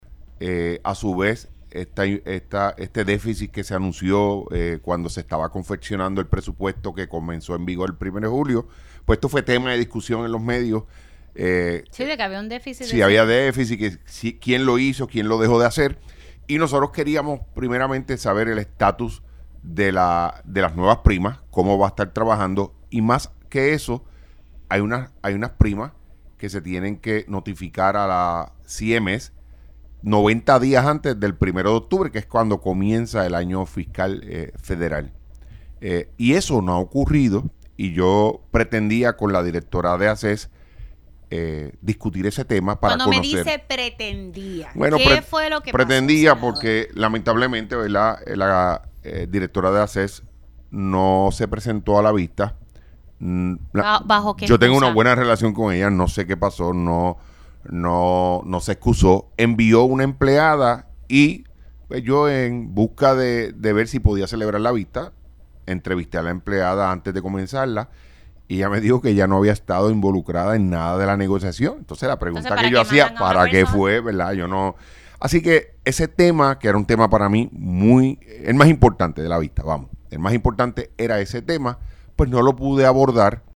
300-JUAN-OSCAR-MORALES-SENADOR-PNP-DIR-DE-ASES-NO-LLEGA-A-VISTA-PUBLIC-PARA-ATENDER-DEFICIT-PRESUPUESTARIO-.mp3